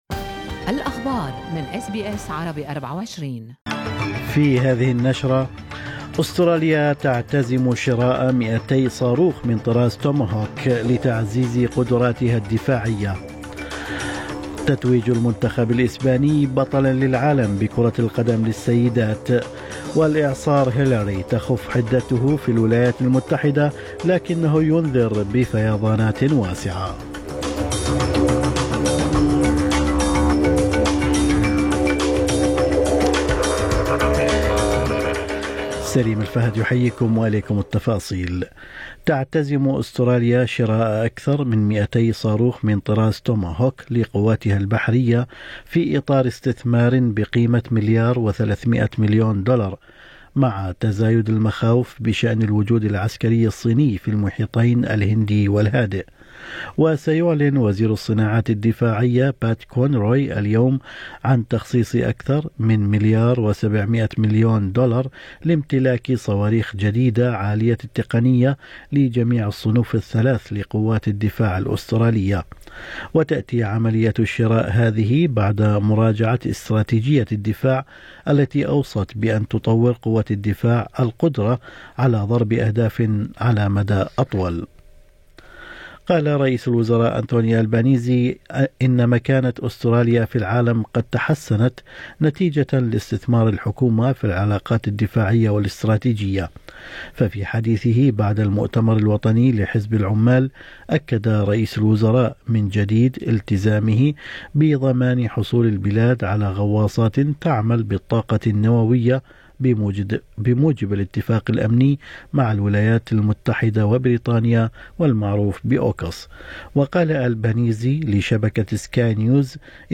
نشرة أخبار الصباح 21/8/2023